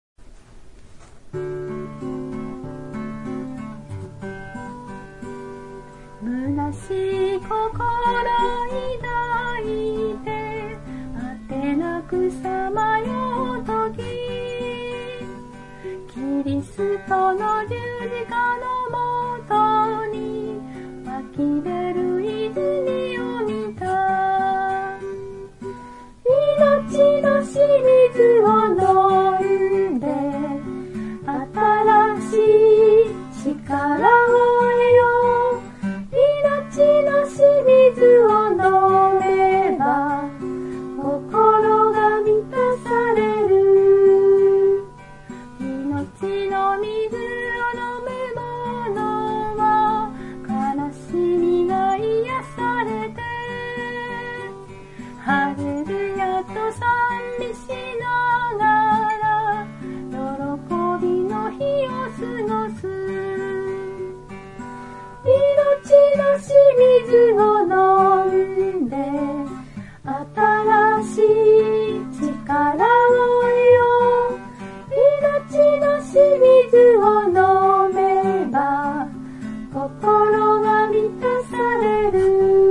33（いのちのしみず（友よ歌おう59） God bless you）より 唄
（徳島聖書キリスト集会集会員）